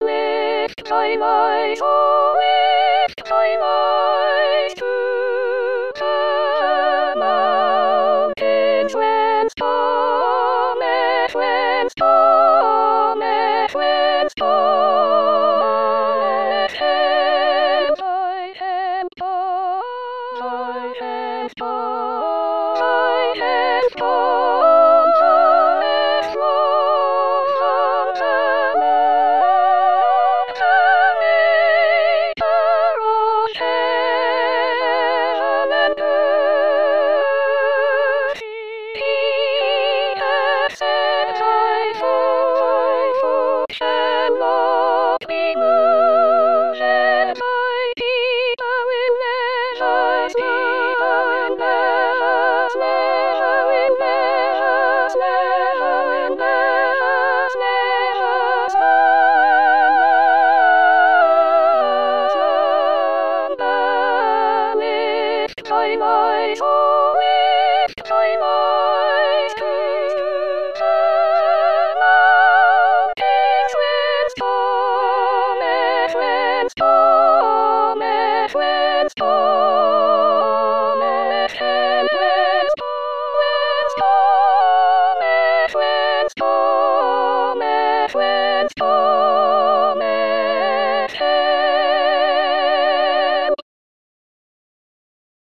Soprano Soprano 1